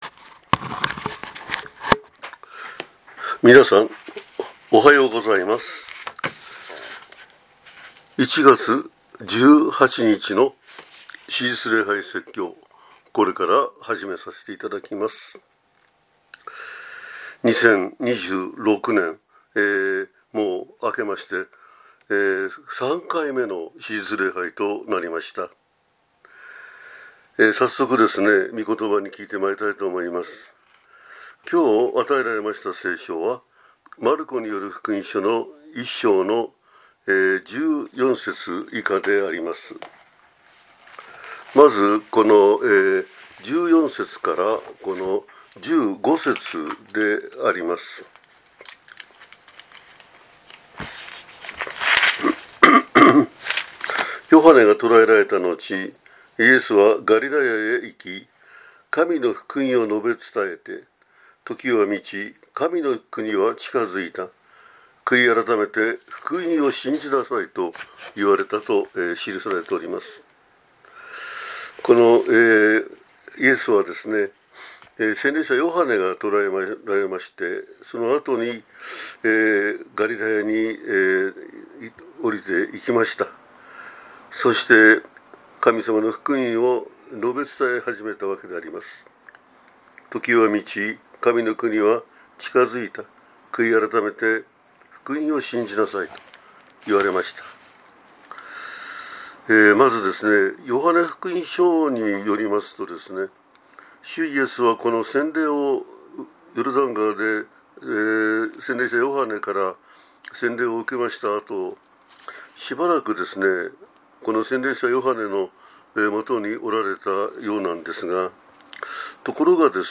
主日礼拝 前奏 招詞 イザヤ書43章18節‐19節 旧1131頁 賛美 8 祈祷 使徒信条 座席